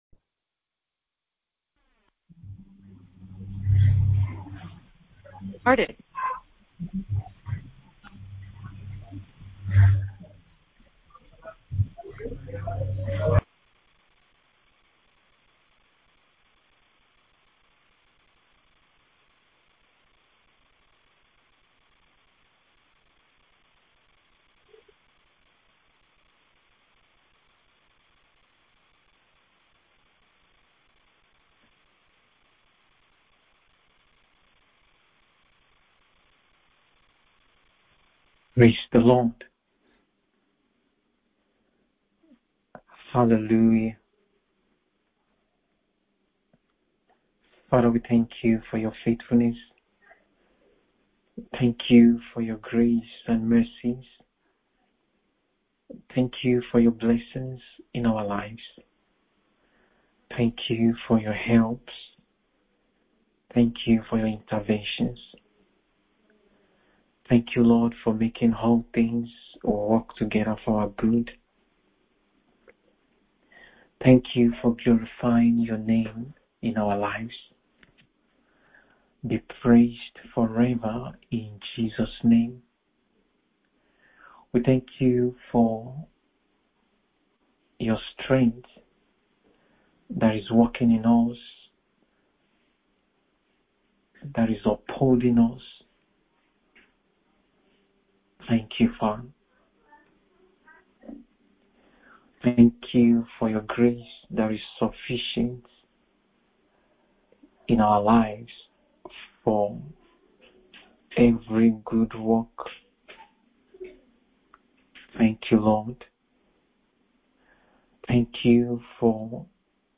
MIDNIGHT PRAYER SESSION : 19 OCTOBER 2024